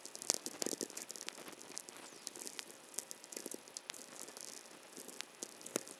Archived Whistler Event Data for 2020-09-23 Forest, VA USA